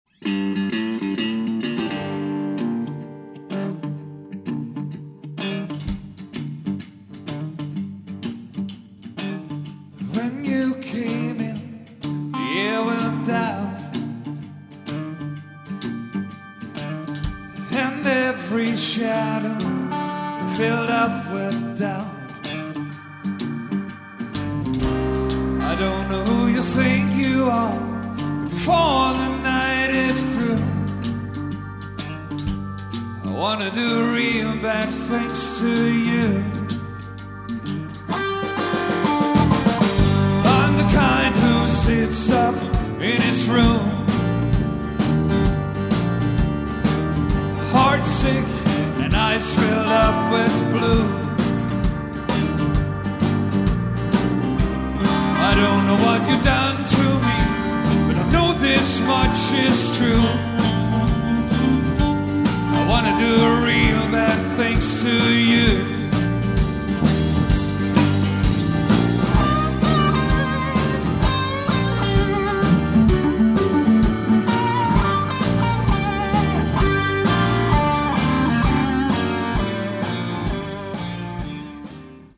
solid cover musik til fester af enhver slags
dansevenlig rock, soul og funk musik
• Coverband
• Rockband